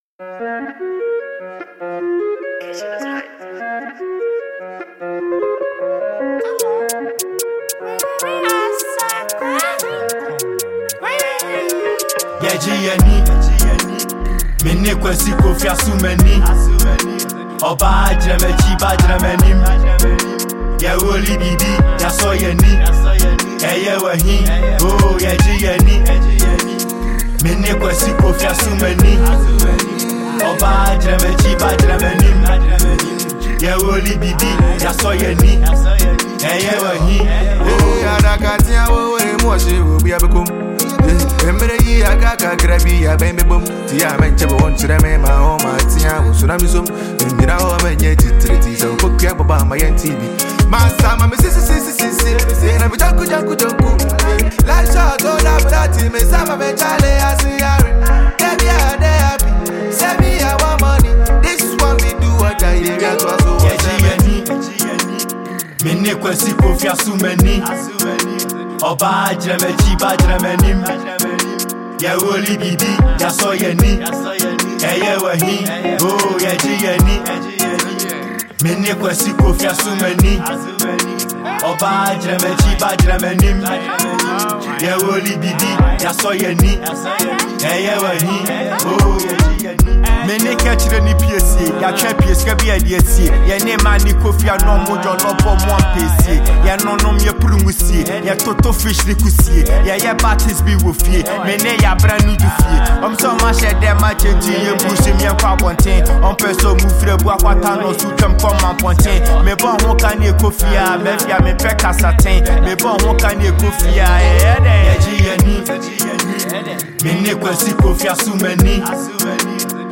Asakaa rapper and songwriter